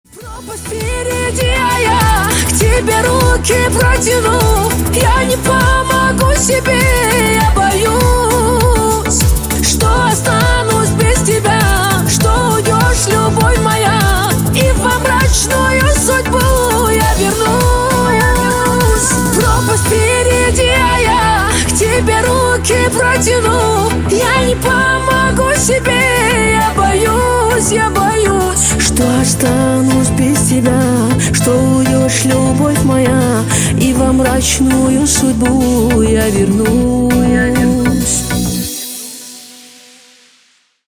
Кавказские Рингтоны
Поп Рингтоны